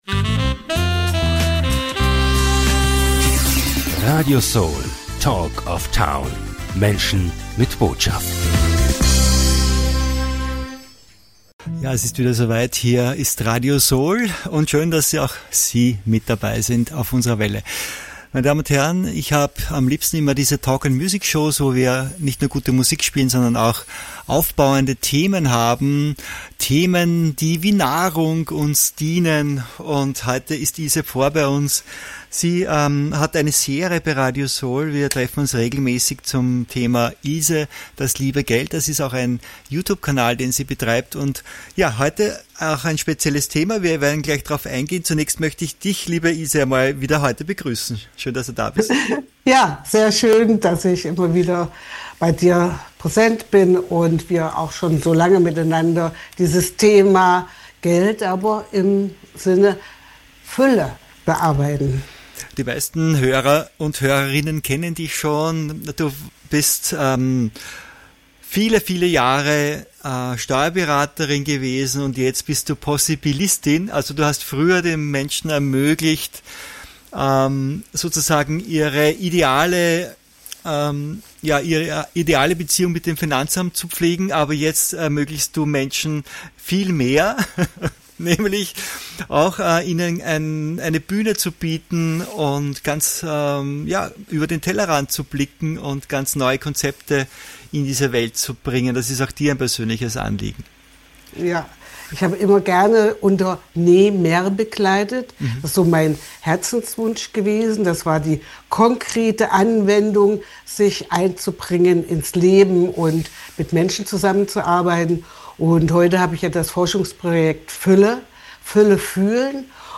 In diesem Interview spricht sie darüber, was wir mehr brauchen: Kontrolle oder Fühlen. Sie zeigt, wie das Fühlen als „Wahrheits-Checker“ dienen kann und uns in ein neues Weltbild führt.